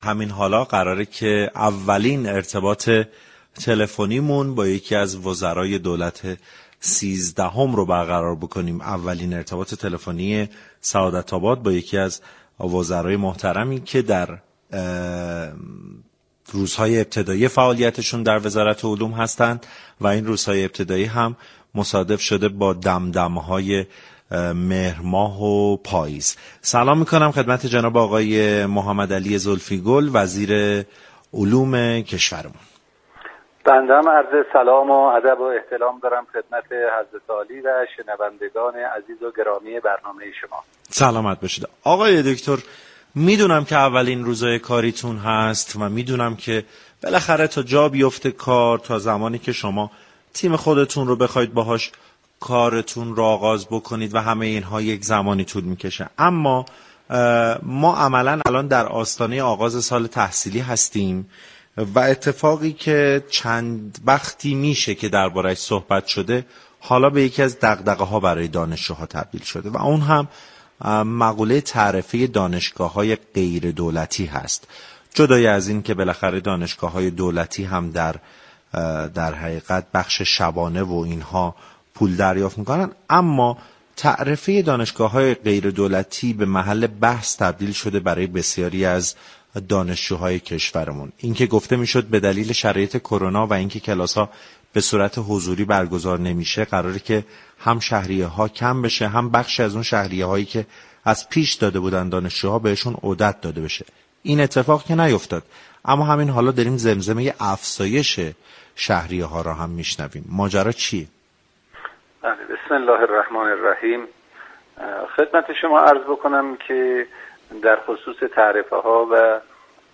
به گزارش پایگاه اطلاع رسانی رادیو تهران، محمدعلی زلفی گل وزیر علوم دولت سیزدهم در گفتگو با برنامه سعادت آباد درخصوص تعرفه های دانشگاه های غیر انتفاعی و افزایش آن گفت: بخشی از آموزش در این دانشگاه ها از ابتدای كرونا به صورت غیرحضوری و مجازی صورت می گیرد كه در برابر آموزش حضوری انرژی و زحمت بیشتری را می‌‌طلبد.